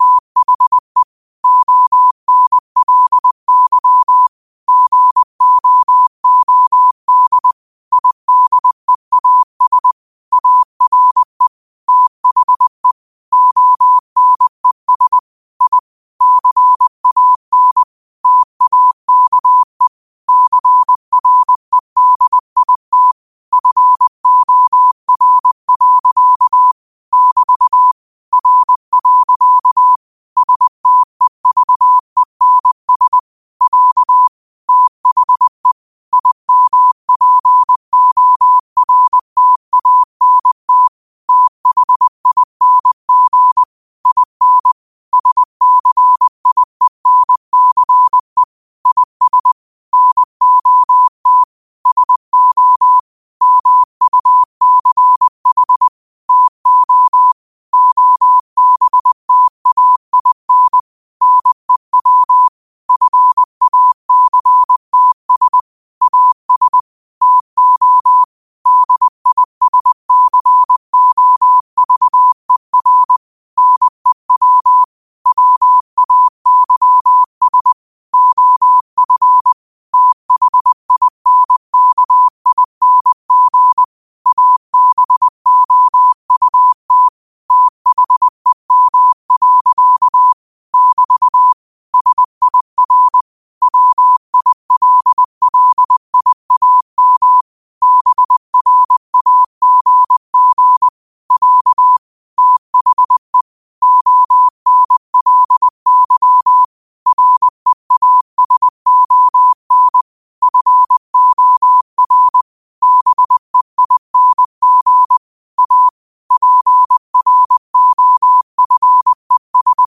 New quotes every day in morse code at 20 Words per minute.